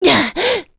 Pavel-Vox_Attack4.wav